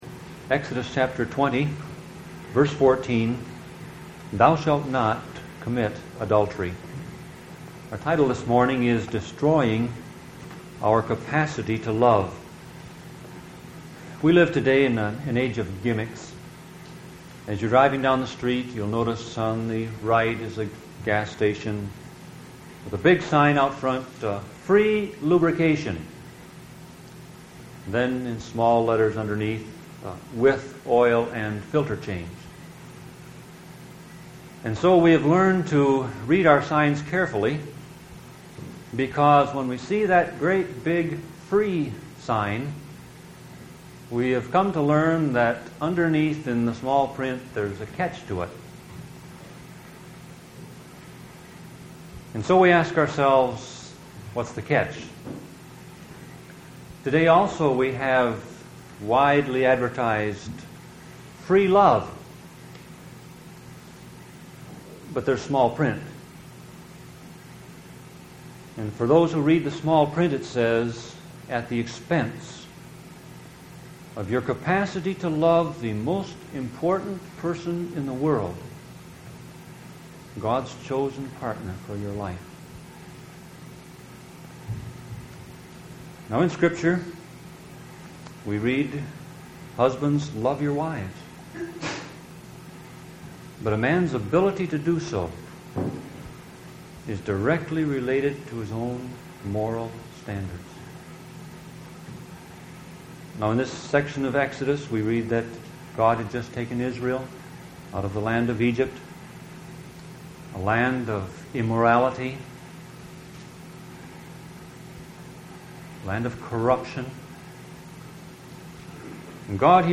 Sermon Audio Passage: Exodus 20:14 Service Type